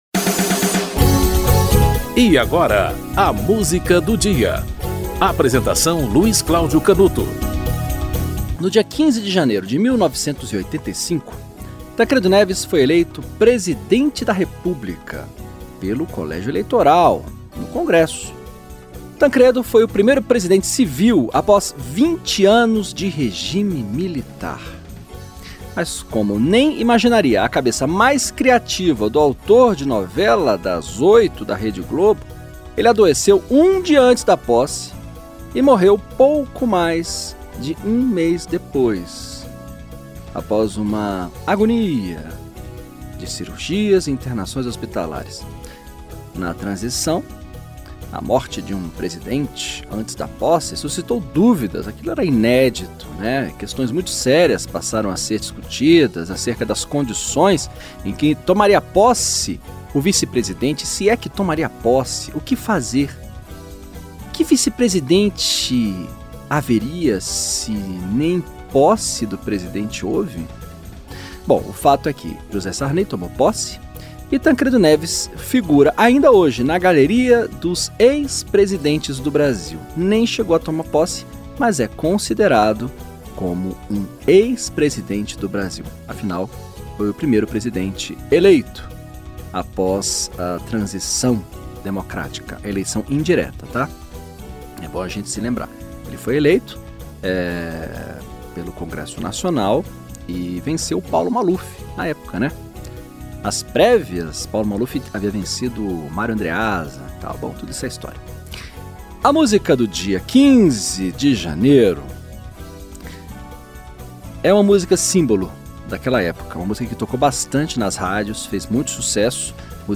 Milton Nascimento - Coração de Estudante (Milton Nascimento e Wagner Tiso)
O programa apresenta, diariamente, uma música para "ilustrar" um fato histórico ou curioso que ocorreu naquele dia ao longo da História.